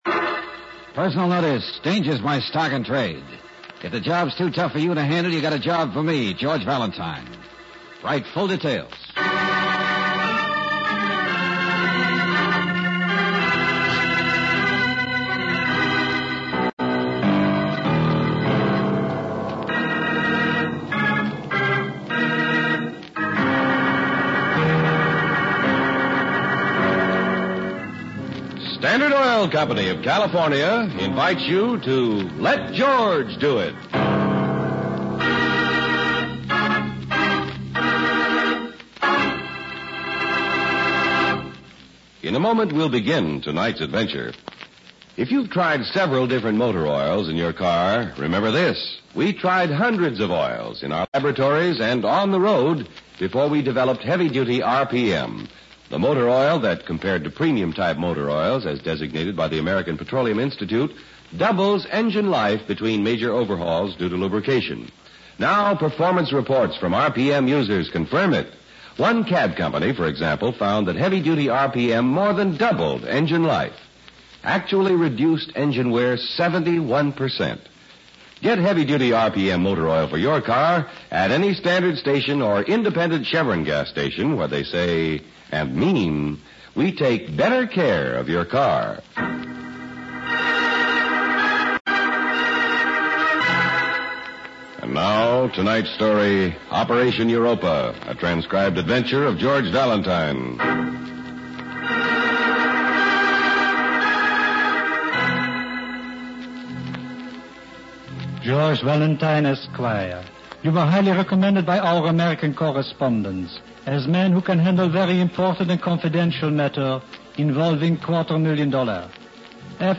starring Bob Bailey
Let George Do It Radio Program